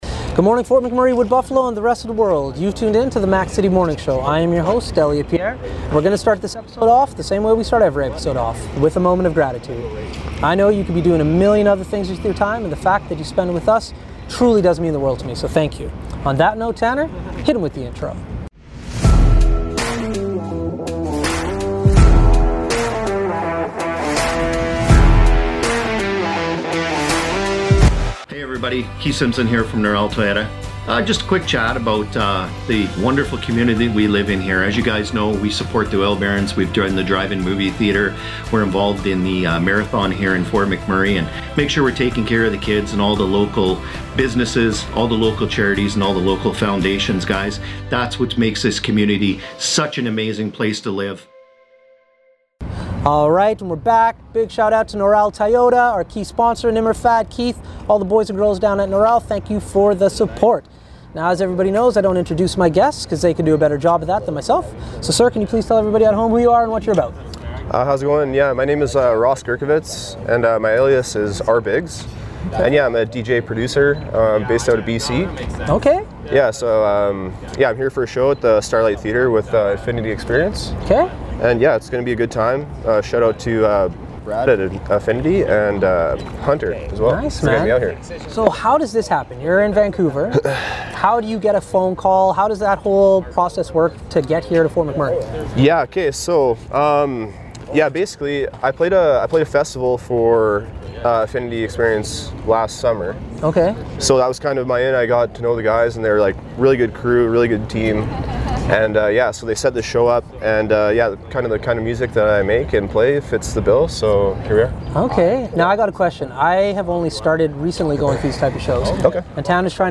The Morning Show went on location to chat with him before his show at the Starlite room in Edmonton.